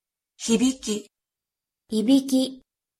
Afin que vous fassiez bien la différence, j’ai mis les deux dans le fichier audio du dessus avec hibiki en premier.
Prononciation-de-hibiki-et-ibiki.mp3